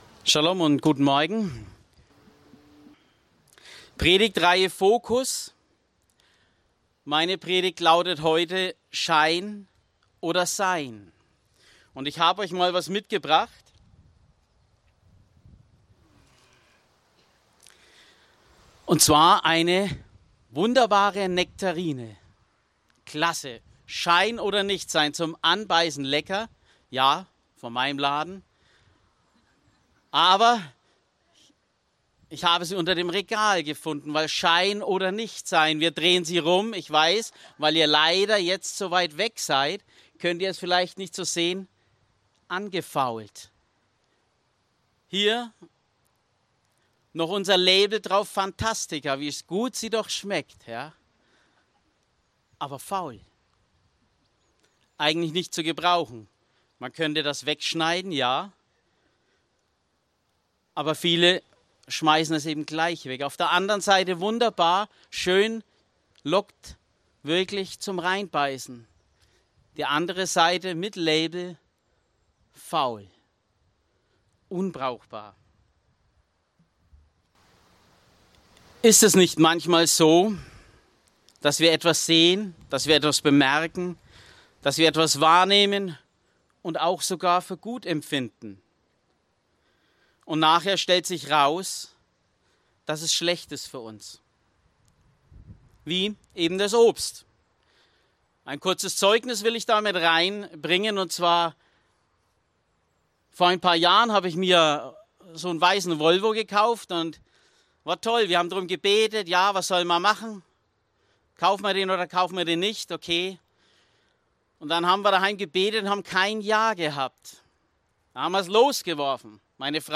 predigte über 2. Timotheus 3,1-9 mit den Unterpunkten Unbewährt im Wandel (V. 1-5) Ungefestigt in der Wahrheit (V. 6-9) HAUSKREISLEITFADEN Aufnahme (MP3) 44 MB Zurück Der Kampf um die Wahrheit Weiter Bleibe standhaft in schweren Zeiten